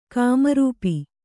♪ kāmarūpi